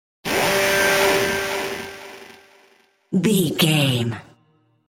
Middle Pain Stinger.
In-crescendo
Thriller
Aeolian/Minor
scary
tension
ominous
dark
eerie
synthesiser